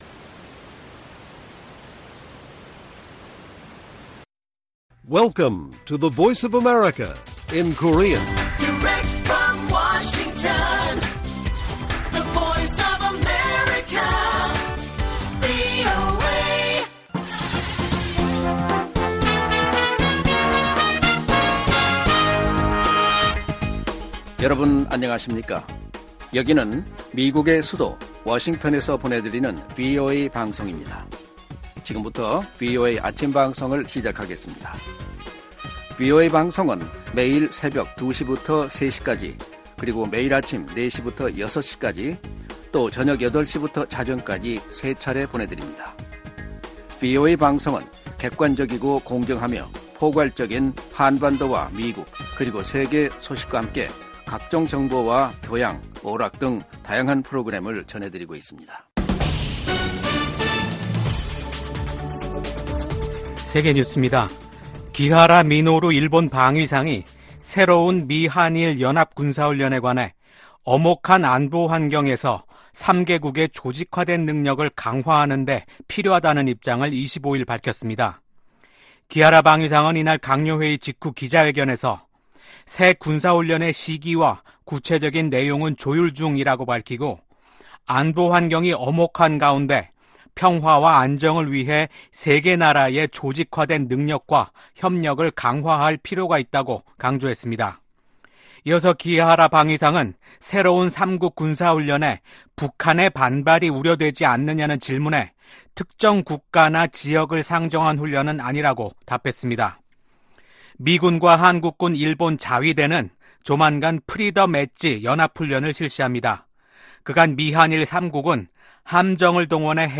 세계 뉴스와 함께 미국의 모든 것을 소개하는 '생방송 여기는 워싱턴입니다', 2024년 6월 26일 아침 방송입니다. '지구촌 오늘'에서는 폭로 전문 사이트 위키리크스의 창립자인 줄리언 어산지 씨가 유죄를 인정하고 석방될 예정인 소식 전해드리고 '아메리카 나우'에서는 미국 연방대법원이 낙태권리를 뒤집은 판결을 내린 지 2년째를 맞아 민주당이 선거운동의 핵심 쟁점으로 삼으면서, 미국 대선에서 다시 낙태문제가 주목받고 있는 소식 전해드립니다.